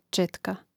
čȅtka četka im. ž. (G čȅtkē, DL čȅtki, A čȅtku, I čȅtkōm; mn. NA čȅtke, G čȅtākā/čȇtkā/čȅtkī, DLI čȅtkama)